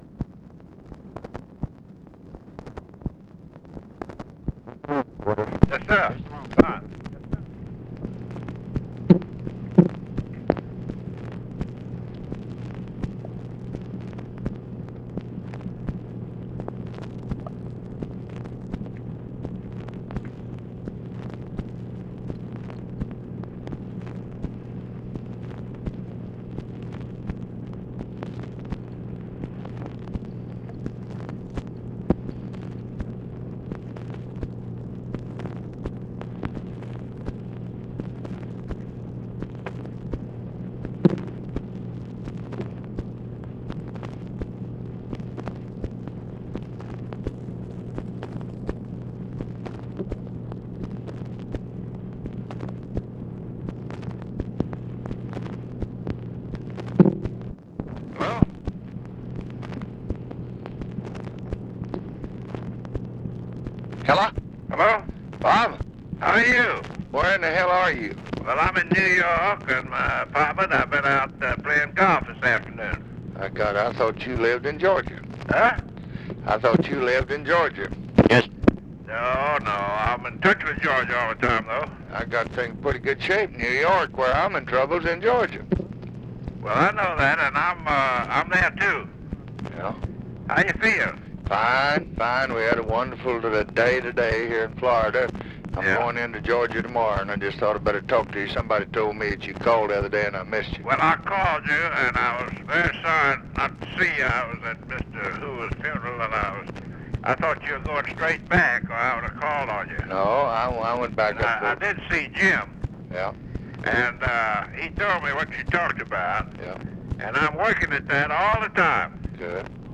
Conversation with ROBERT WOODRUFF, October 25, 1964
Secret White House Tapes